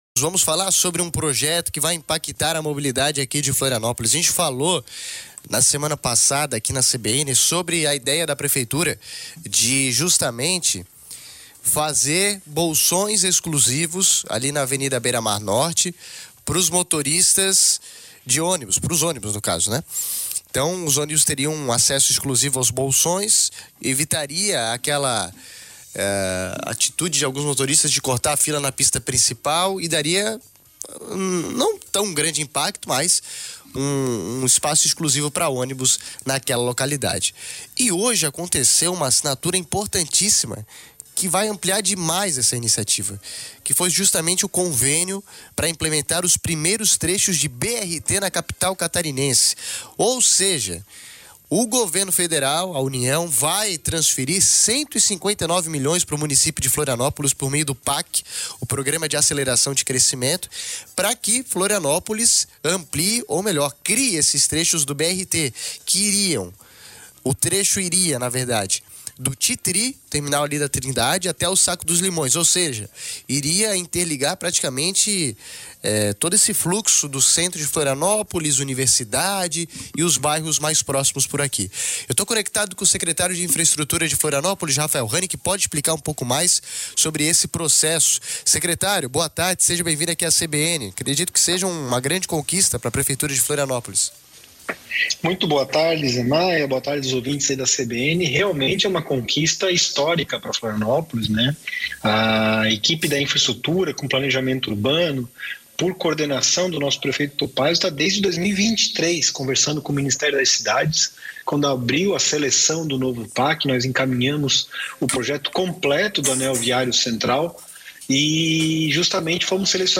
Confira a entrevista à CBN Floripa com o secretário de Infraestrutura de Florianópolis, Rafael Hahne: